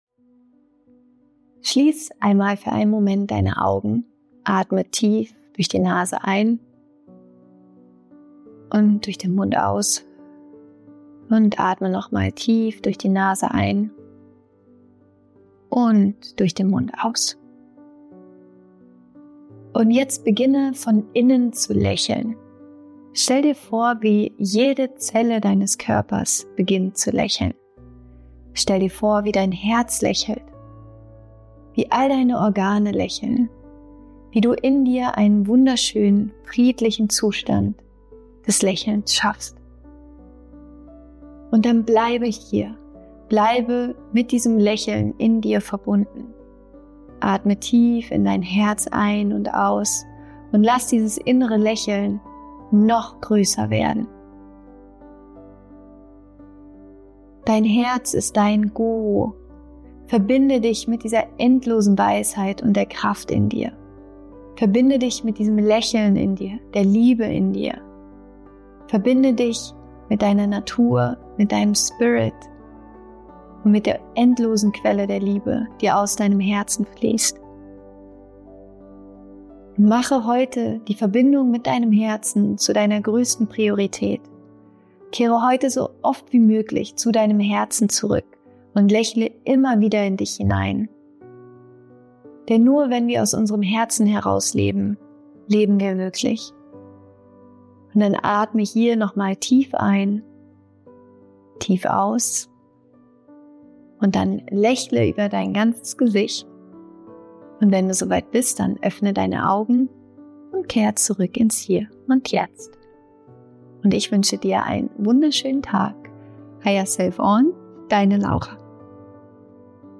Diese kurze Meditation ist eine sanfte Einladung, dich heute wieder mit deinem Herzen zu verbinden.